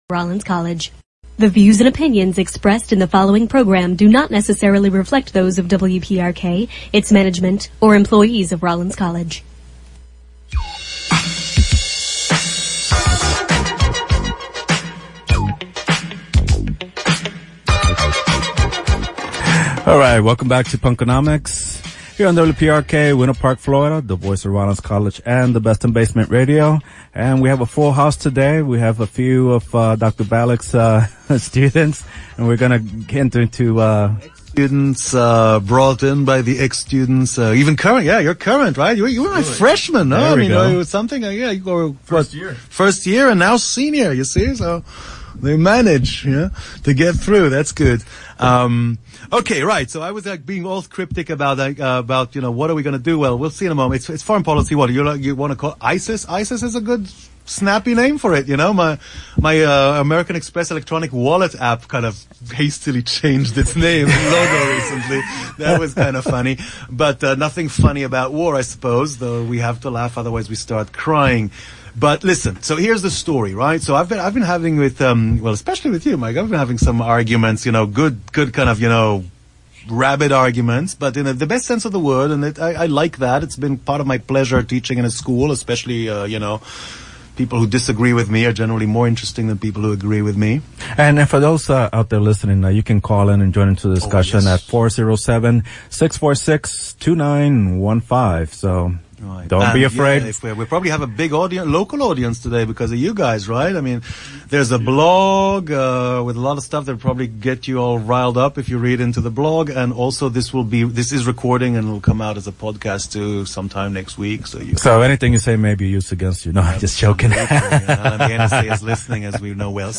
We had a lively argument with some of my students in the studio about current US foreign policy in its economic and historical context.